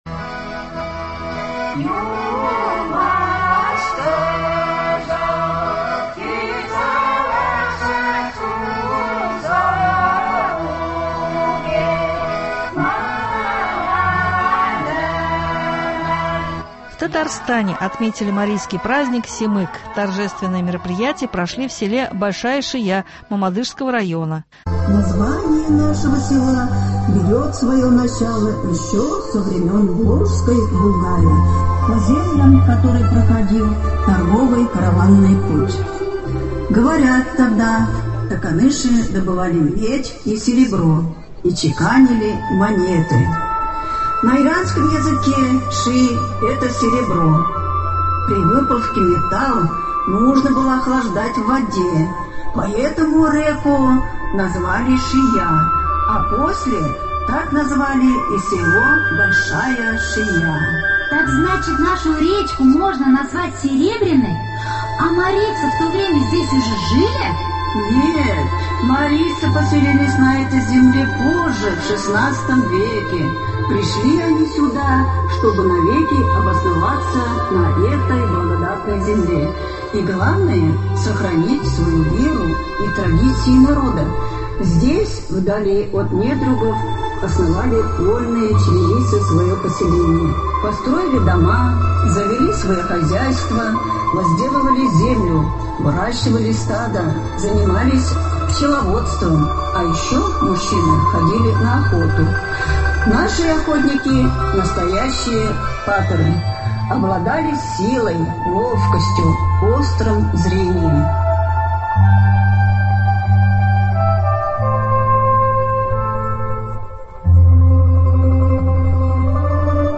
у нас в студии